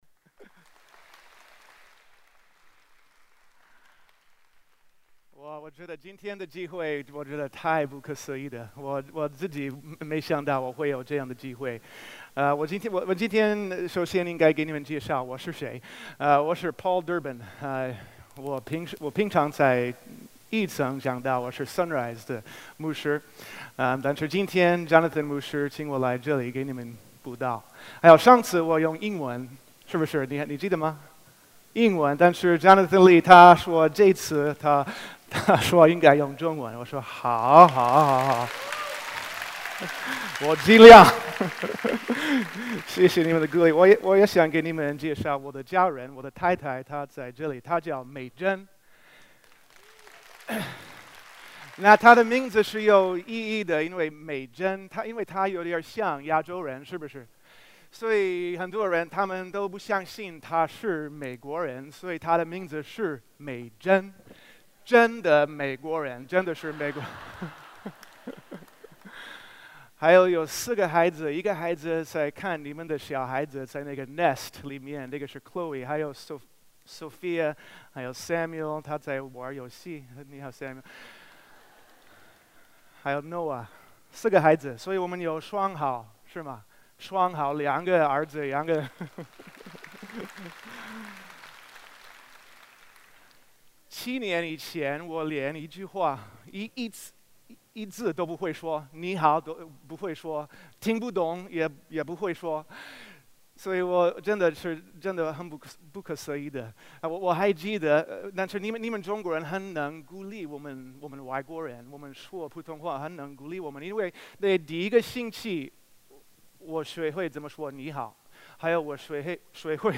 主日证道 | 无限的神